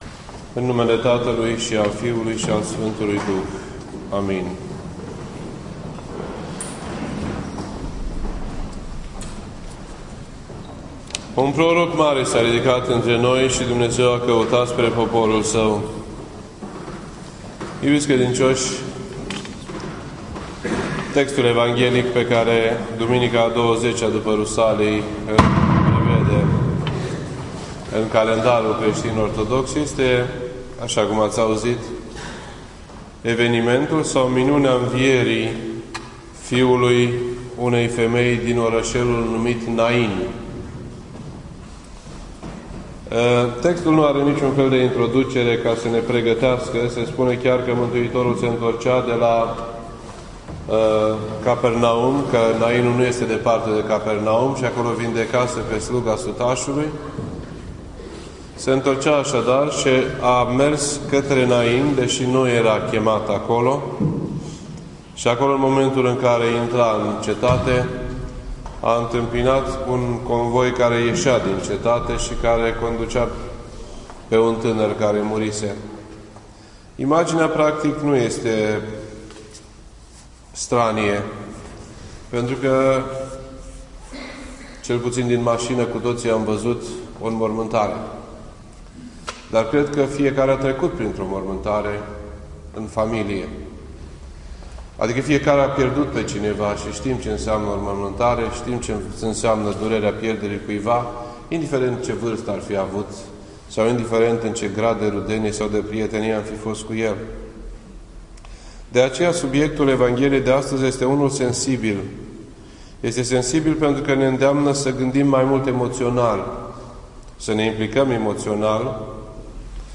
This entry was posted on Sunday, October 19th, 2014 at 12:20 PM and is filed under Predici ortodoxe in format audio.